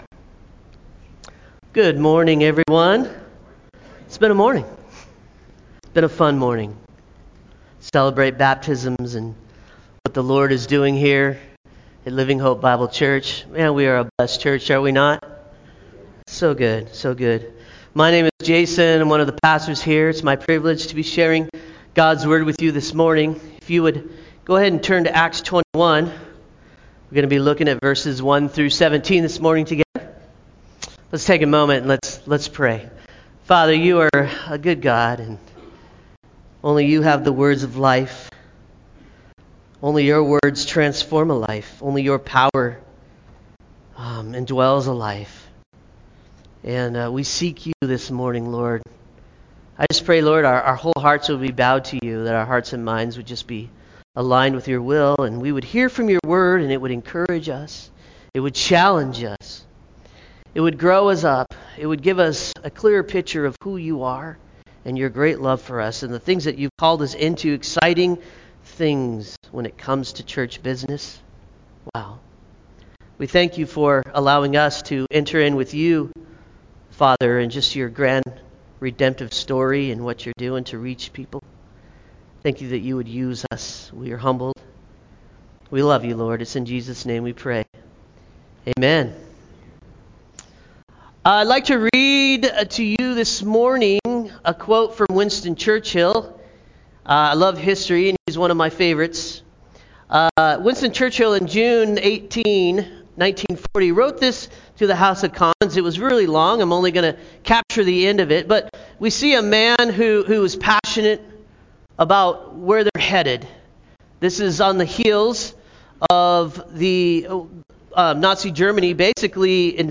Related Topics: sermon